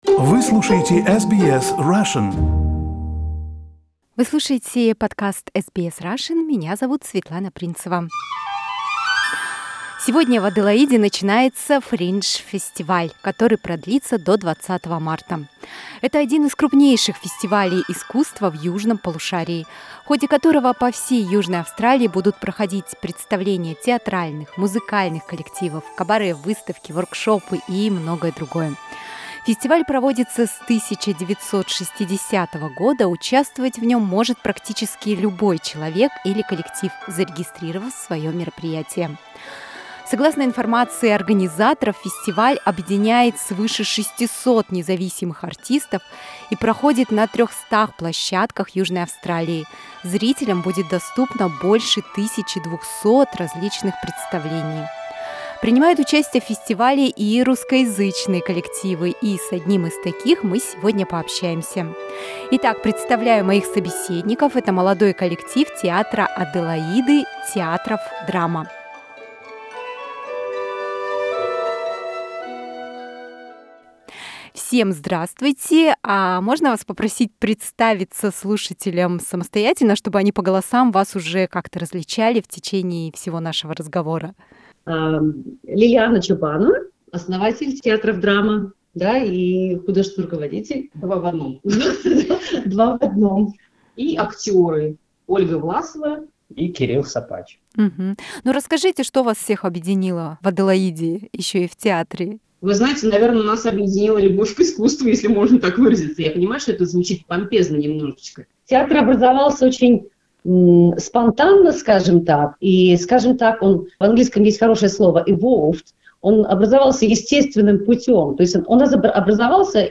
Мы поговорили с участниками коллектива "Teatroff Drama", которые рассказали о своей постановке для фестиваля Adelaide Fringe 2022.